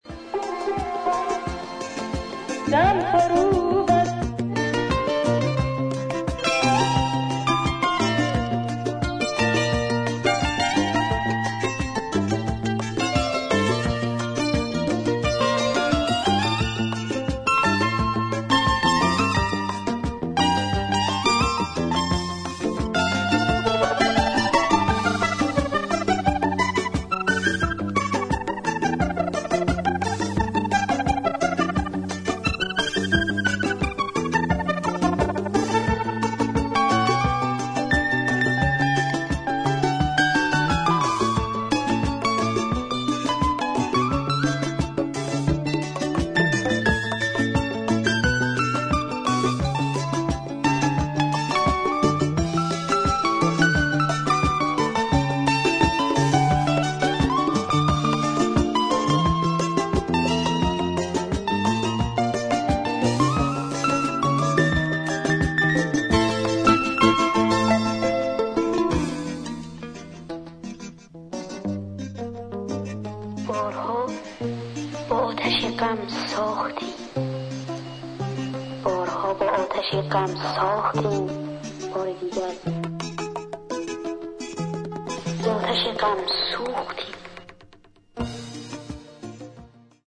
土着的なアフリカものからニューウェーブ、ダブ、ロック、現代音楽までジャンルをクロスオーバーした良作が多数収録